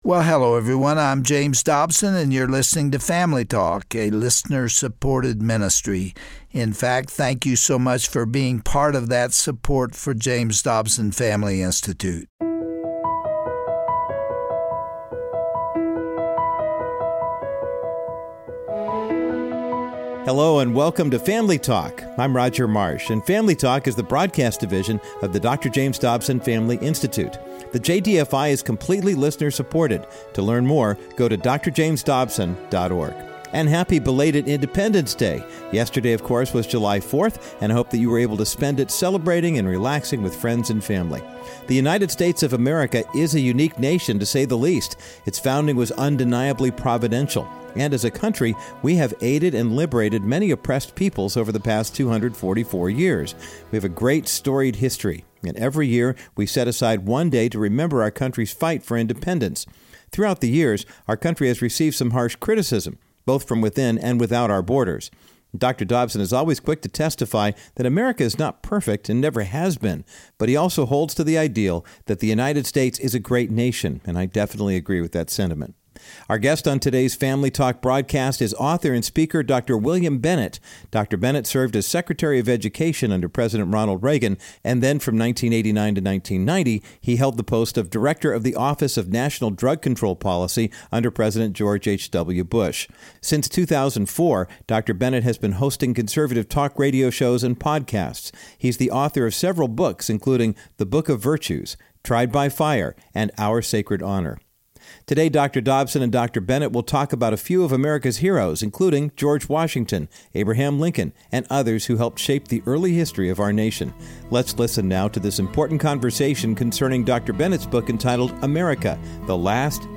On today's edition of Dr. James Dobson's Family Talk, and as we celebrate Independence Day weekend, Dr. James Dobson sits down with Dr. William Bennett to discuss our country's extraordinary history and read excerpts from his 3-volume series, America: The Last Best Hope. They touch on stirring examples of American exceptionalism and warn that this great experiment in liberty and democracy is not a foregone conclusion.
Host Dr. James Dobson